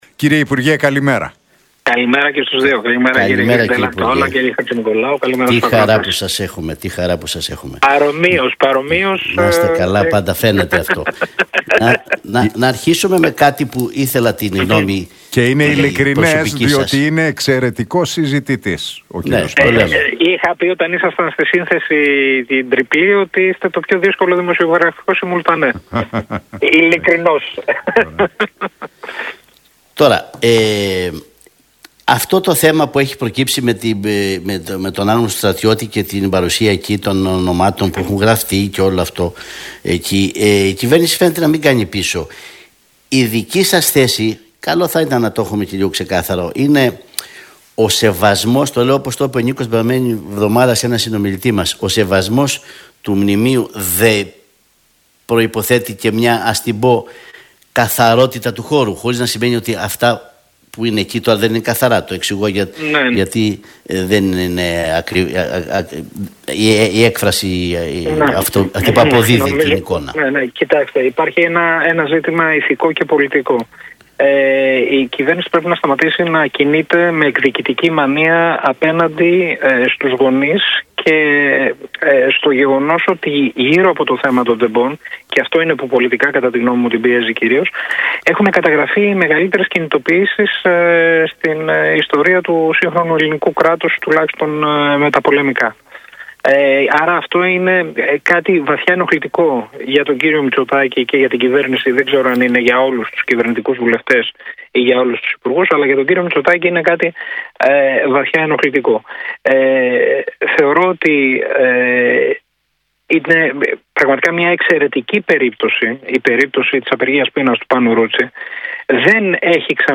Για το Μνημείο του Αγνώστου Στρατιώτη, τα ελληνοτουρκικά αλλά και τις εξελίξεις στον ΣΥΡΙΖΑ και γενικότερα στην Κεντροαριστερά μίλησε στον Realfm 97,8 και